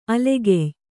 ♪ alegey